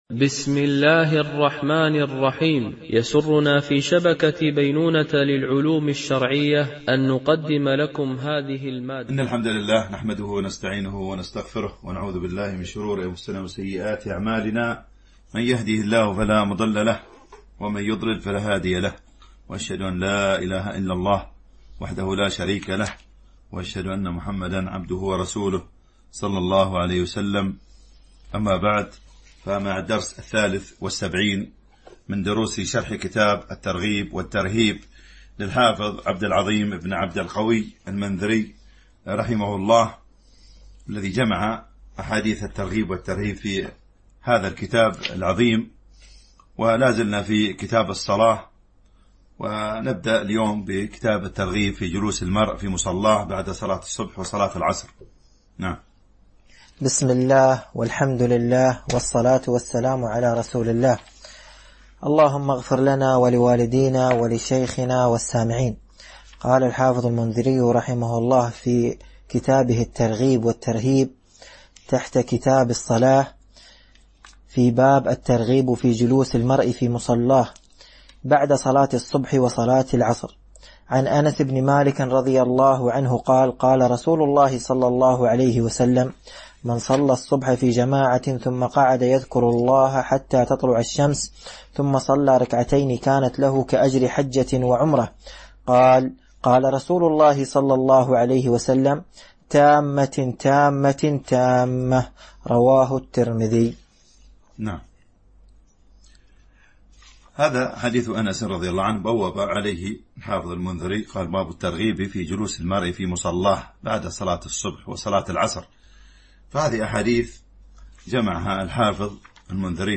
شرح كتاب الترغيب والترهيب - الدرس 73 ( كتاب الصلاة .الحديث 671 - 681)